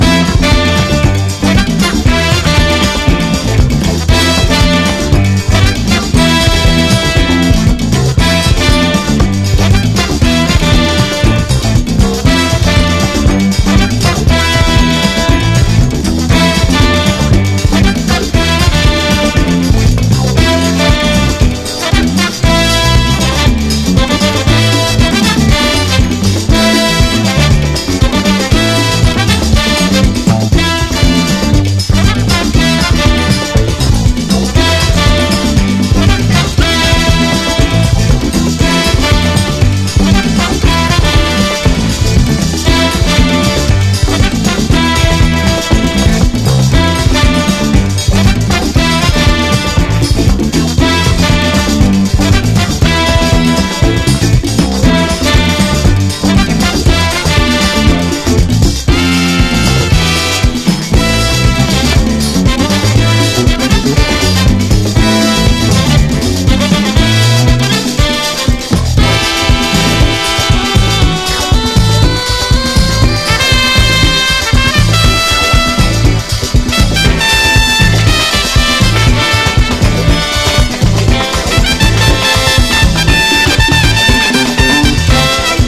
JAPANESE HOUSE / HOUSE / CLUB JAZZ